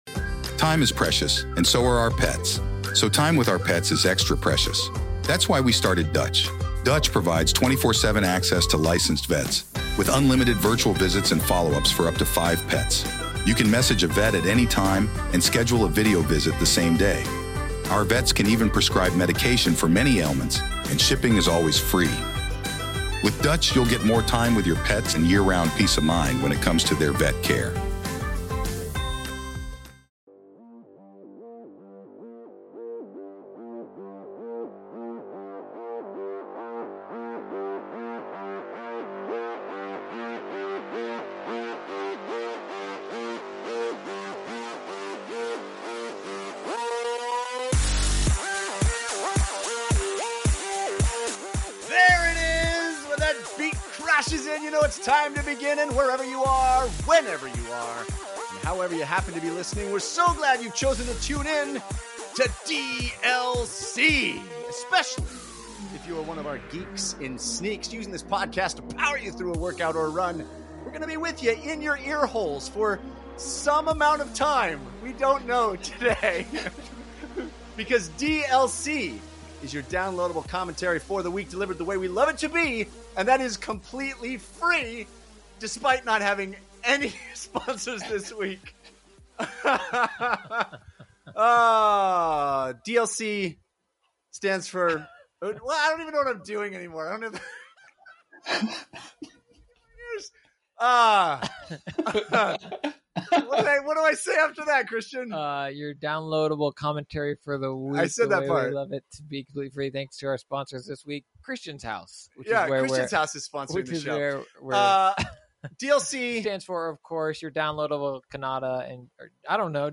The result is a wacky, chaotic episode unlike any other!